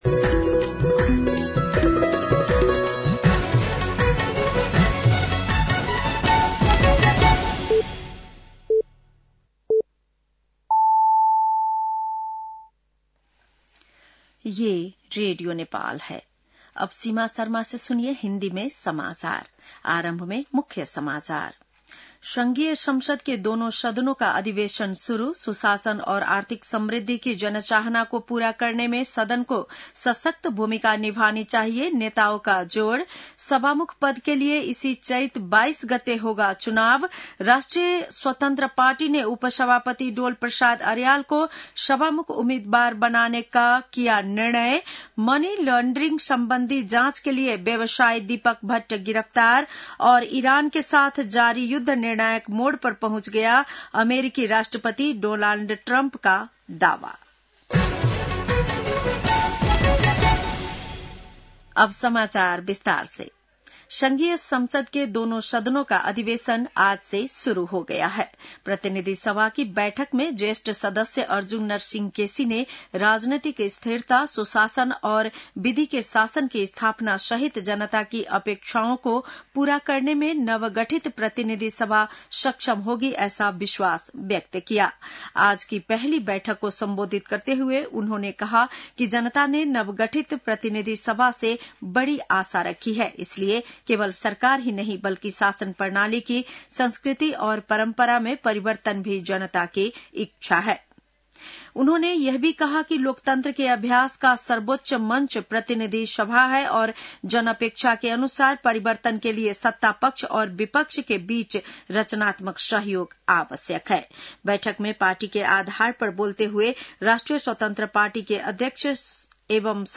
बेलुकी १० बजेको हिन्दी समाचार : १९ चैत , २०८२